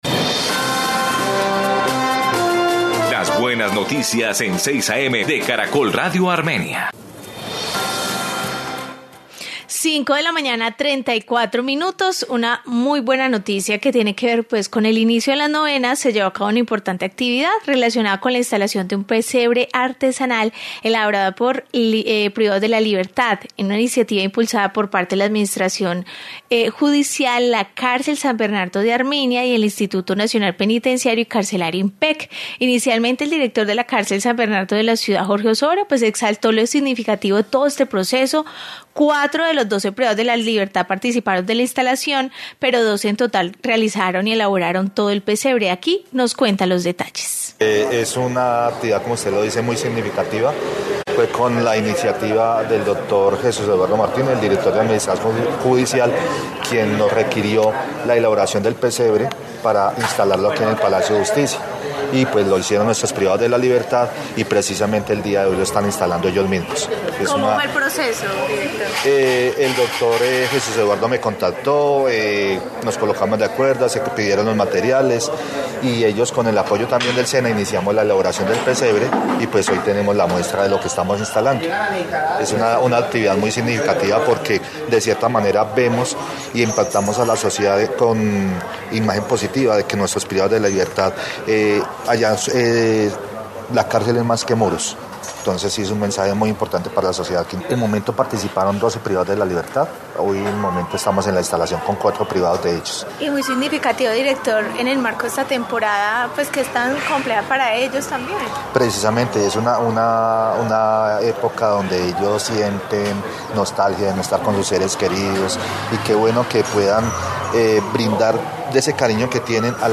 Informe sobre privados de la libertad